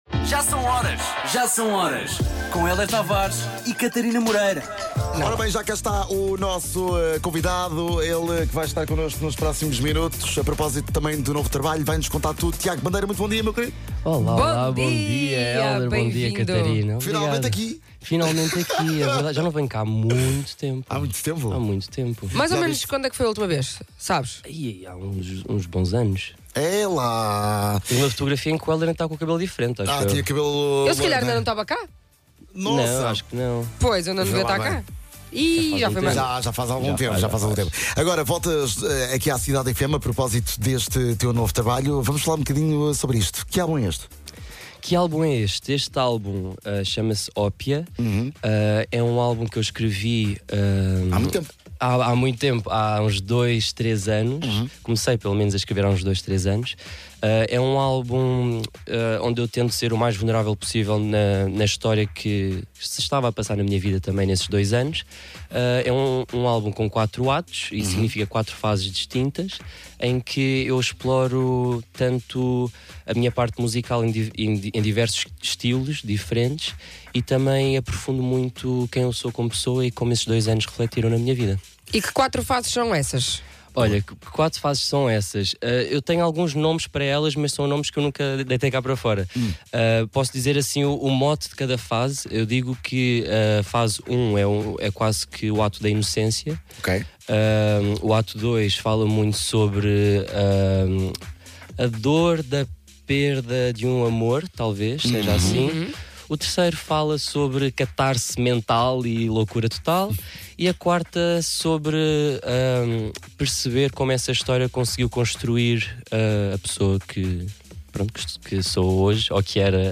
veio até ao Já São Horas falar sobre ele e ainda tocou um dos singles no nosso momento Freshzone